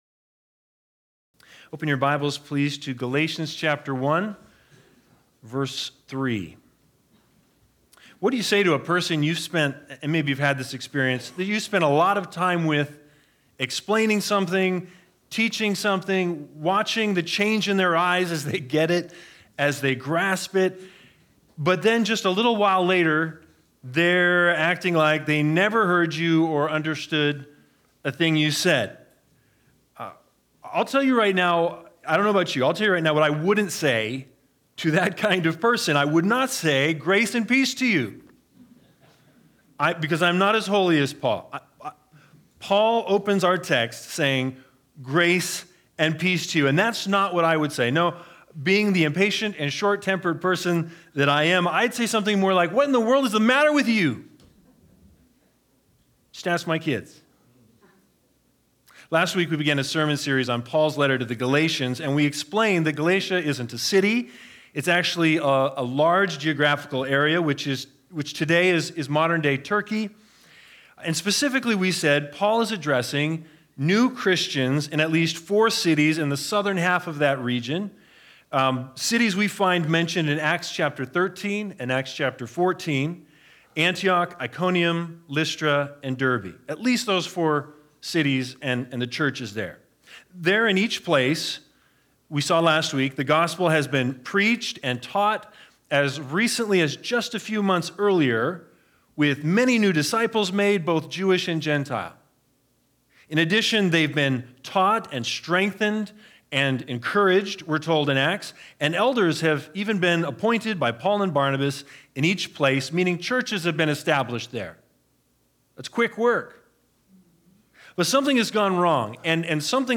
Service Type: Sunday Sermons